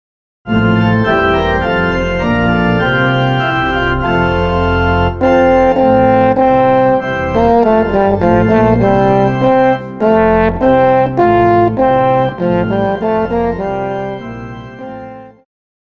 Classical
French Horn
Band
Instrumental
Only backing